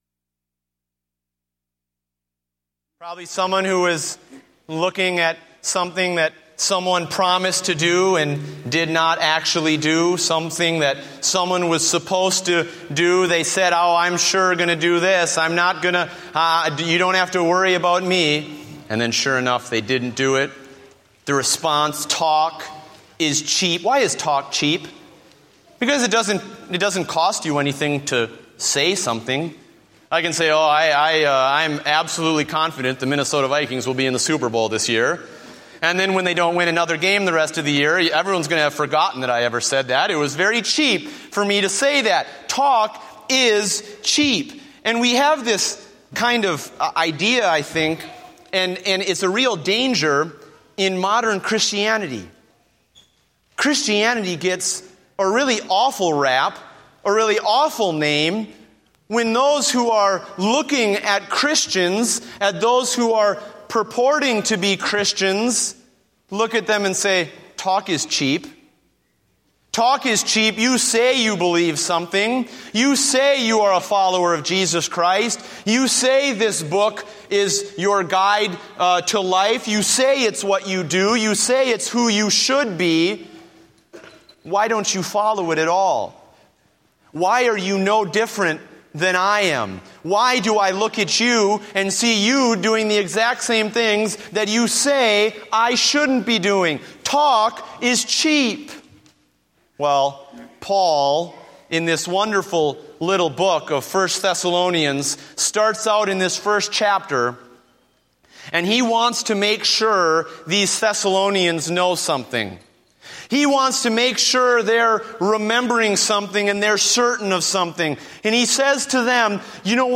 Date: October 19, 2014 (Morning Service)